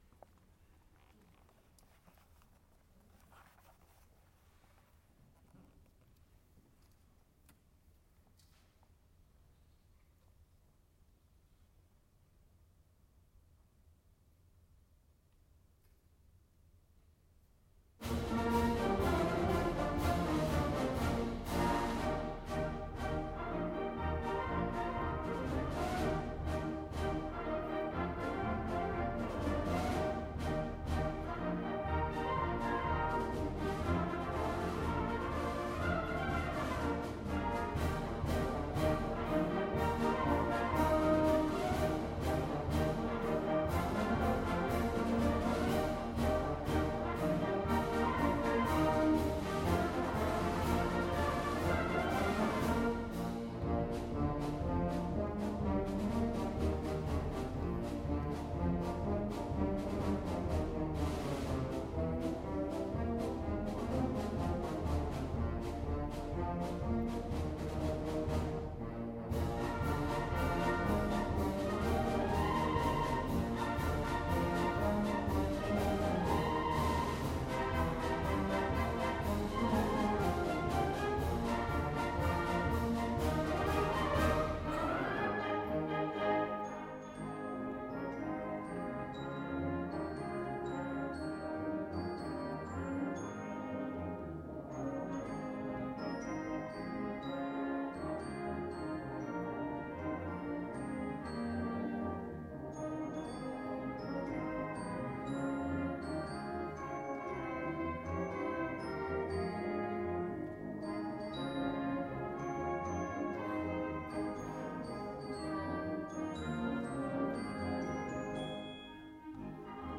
MARCH MUSIC